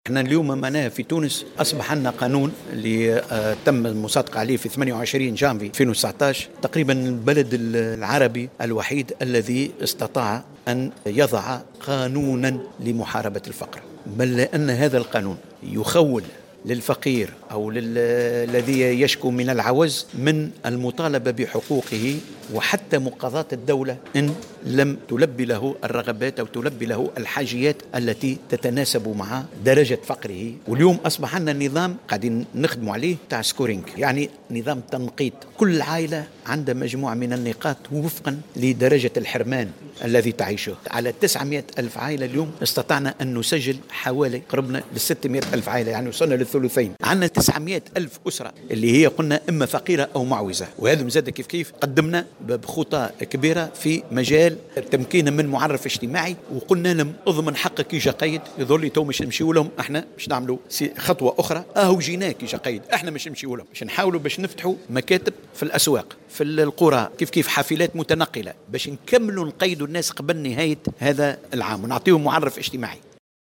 وأضاف الطرابلسي في تصريح لمراسلتنا بالجهة على هامش الملتقى الإقليمي حول الخدمة الإجتماعية في منطقة الشرق الأوسط وشمال إفريقيا المنعقد بالحمامات أنّ الوزارة أعدّت خطة تدخل استثنائية تستفيد منها 285 ألف عائلة معوزة، مشيرا إلى أنه سيتم اعتماد نظام تنقيط يمنح عددا من النقاط للأسر المعوزة لتحديد مستوى الفقر وبذلك يكون تدخل الدولة أكثر نجاعة.